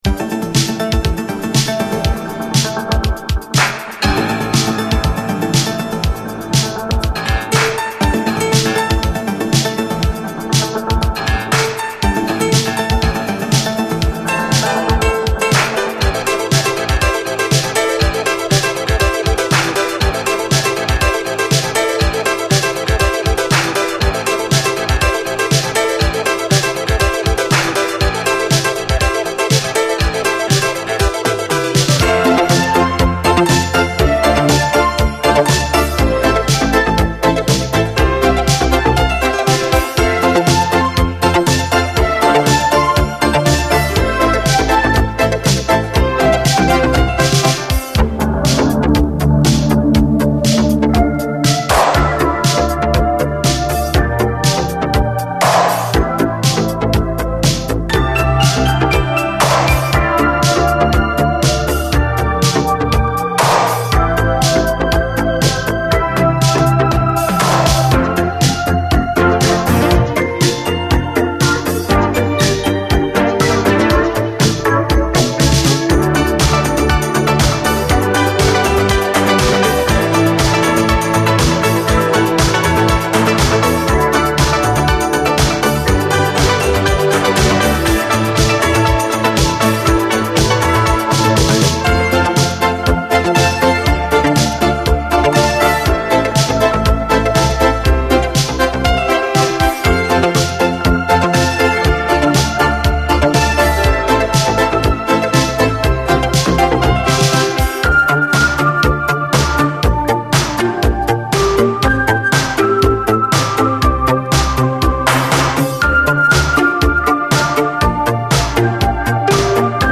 DISCO
83年メロウ・コズミック・イタロ！キラキラしたファンシーさとダークでコズミックな要素が融合した一筋縄ではいかない展開！